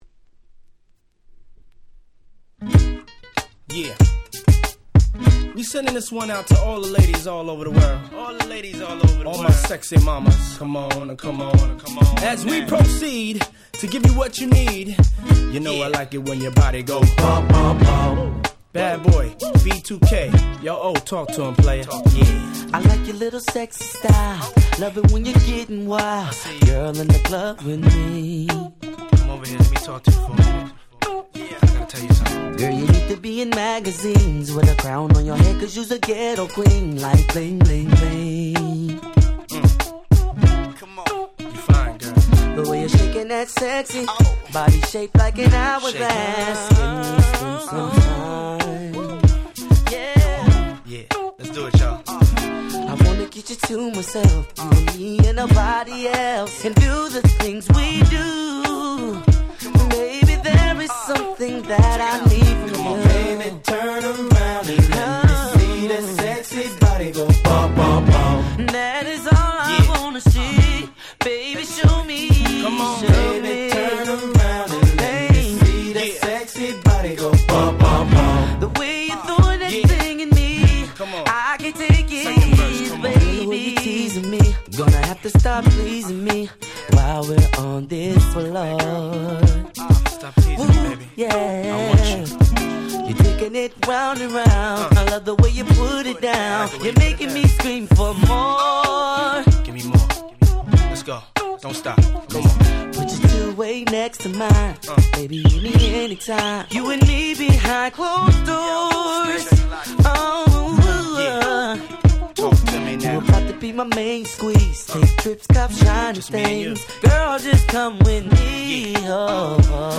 02' Super Hit R&B !!